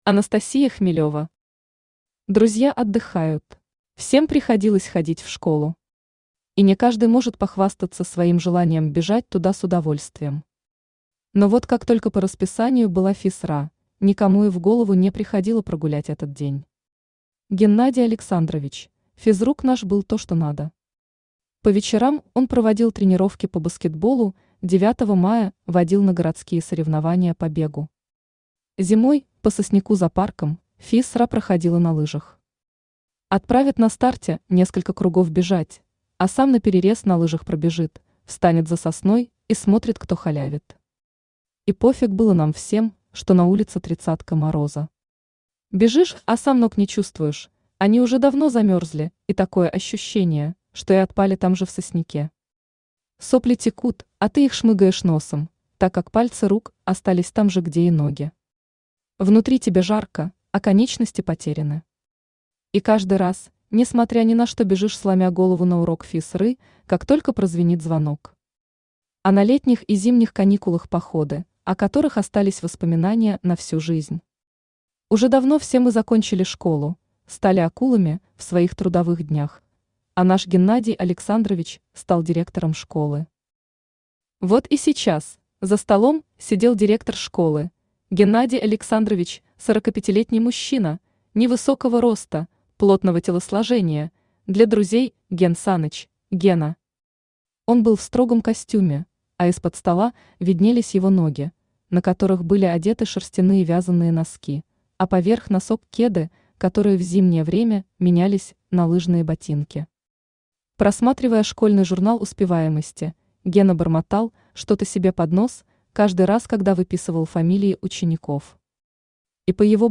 Аудиокнига Друзья отдыхают | Библиотека аудиокниг